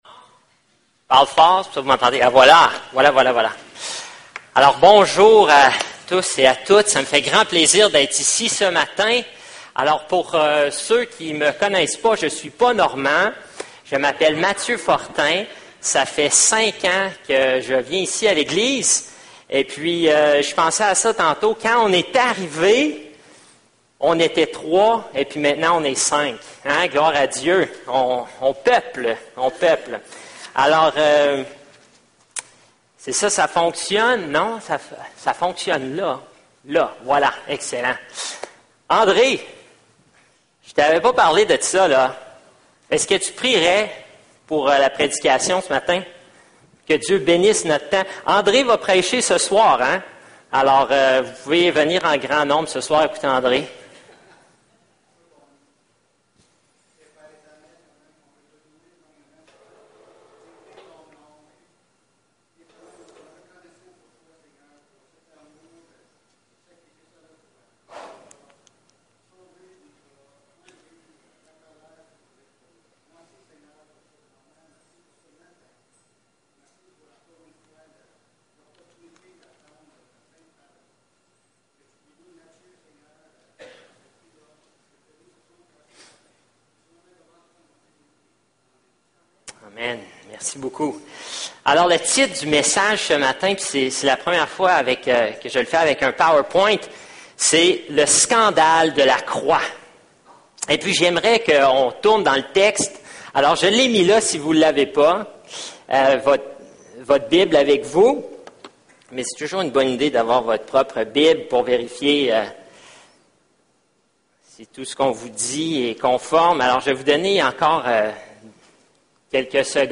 1 Corinthiens 1:18-25 Service Type: Célébration dimanche matin Confronté à la croix de Jésus